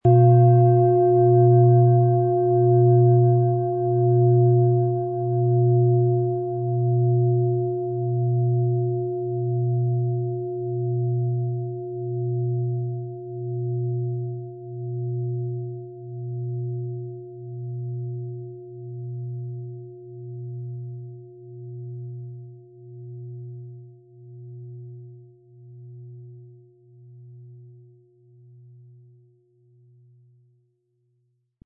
• Tiefster Ton: Mond
Der Klöppel lässt die Klangschale voll und harmonisch tönen.
MaterialBronze